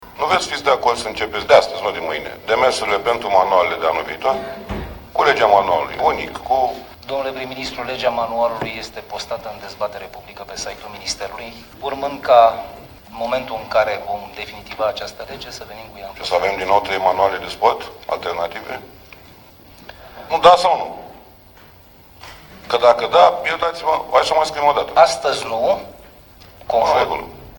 Iată dialogul dintre prim-ministrul Mihai Tudose și Gigel Paraschiv, secretar de stat în Ministerul Educației:
28sept-DESTEPTAREA-Voce-Tudose-Paraschiv-manualul-de-sport-sed-de-Guvern.mp3